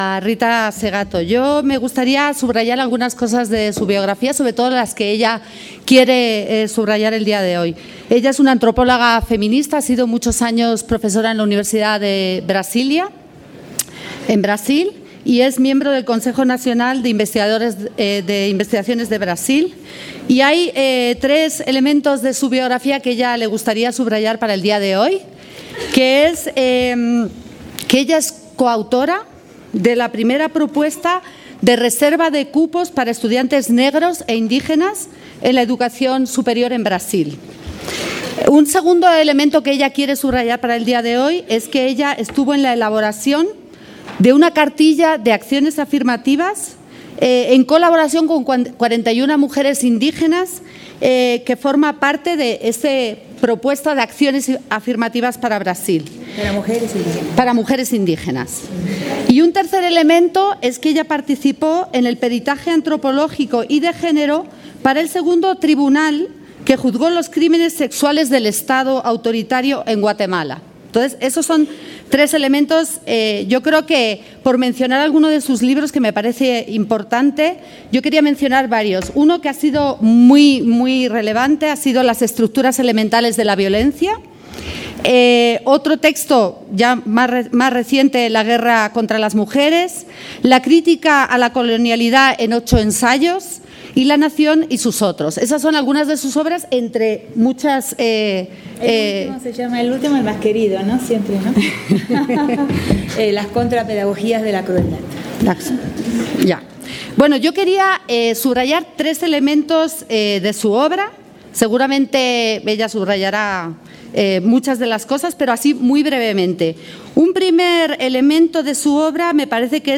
Segato, Rita (Expositor)